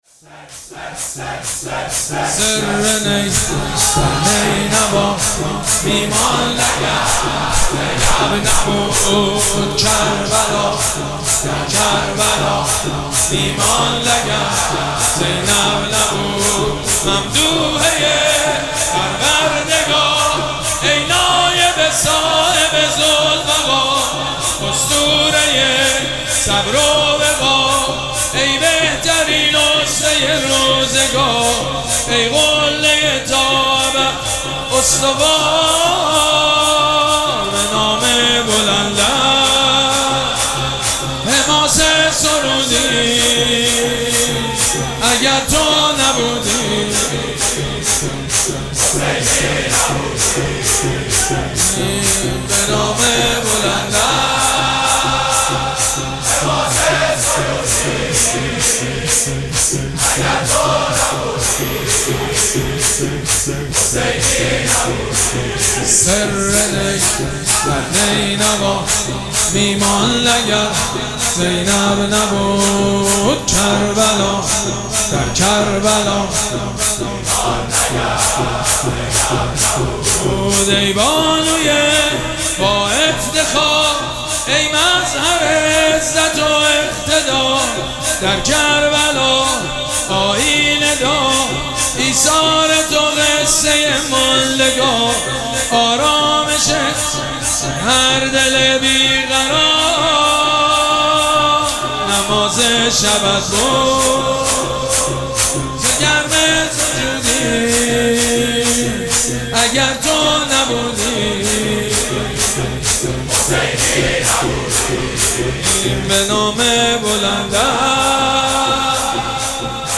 مراسم عزاداری شب هفتم محرم الحرام ۱۴۴۷
شور
مداح
حاج سید مجید بنی فاطمه